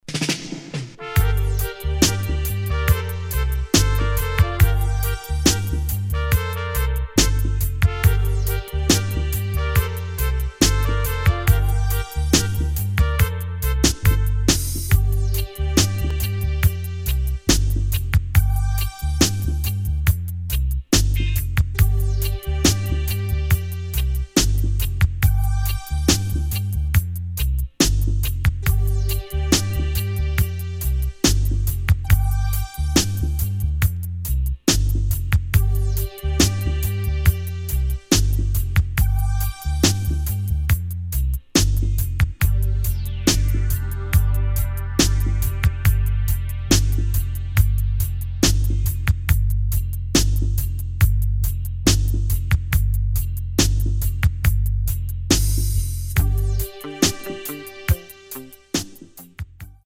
[ REGGAE / DUB ]